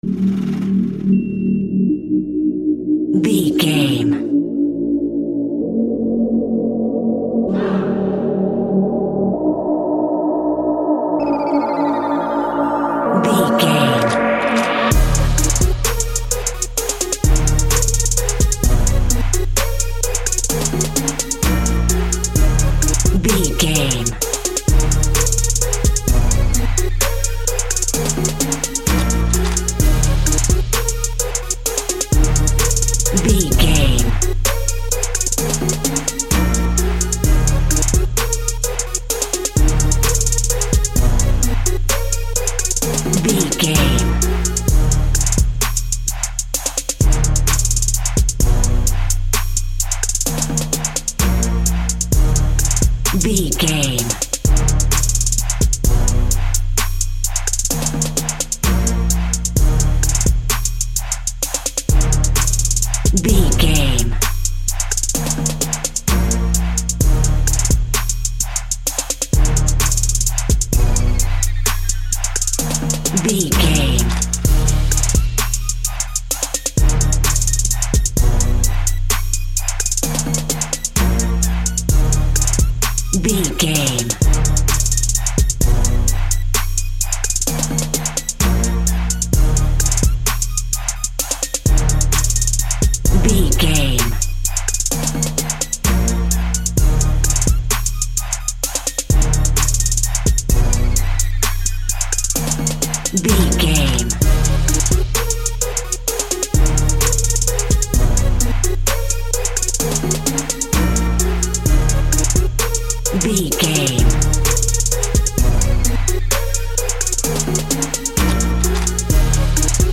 Huge Orchestral Hip Hop.
Epic / Action
Aeolian/Minor
E♭
chilled
laid back
hip hop drums
hip hop synths
piano
hip hop pads